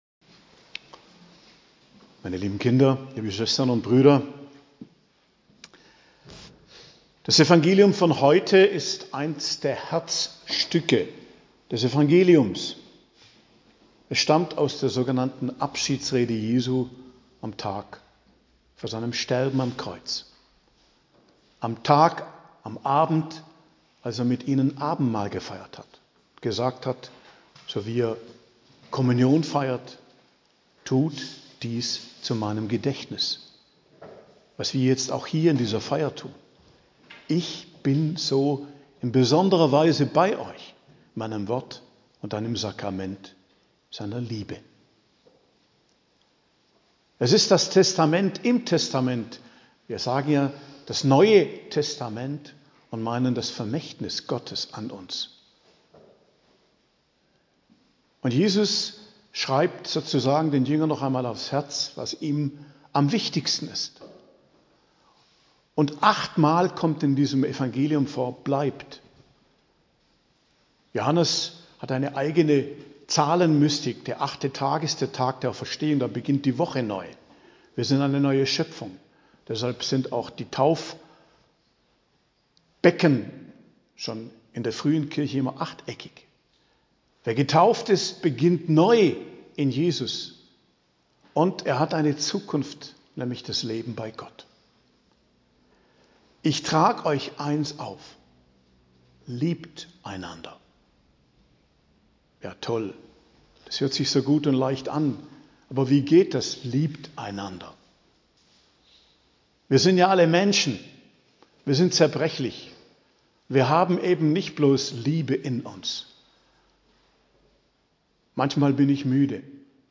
Predigt zum 6. Sonntag der Osterzeit, 5.05.2024